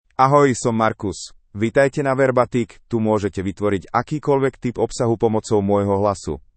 MaleSlovak (Slovakia)
MarcusMale Slovak AI voice
Voice sample
Marcus delivers clear pronunciation with authentic Slovakia Slovak intonation, making your content sound professionally produced.